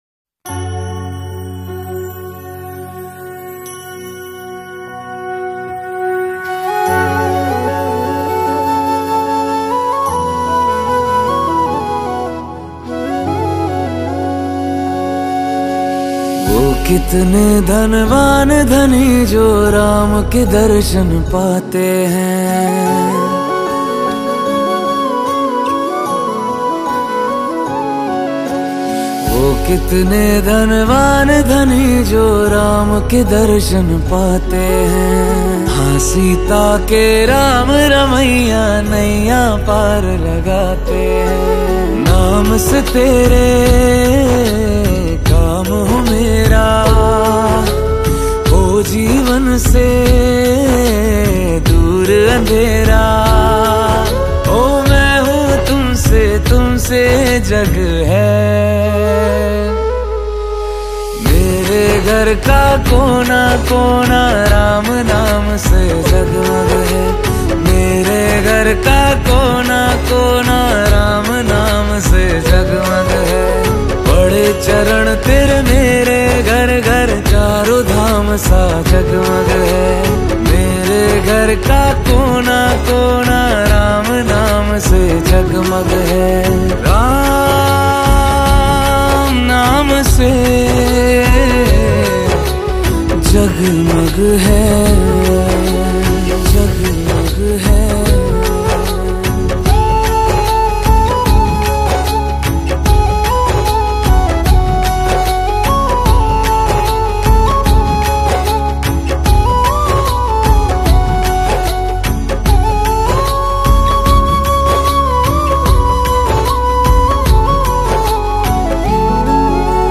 Hindi Bhakti Song
Ram Bhajan